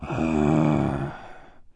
spawners_mobs_mummy_neutral.1.ogg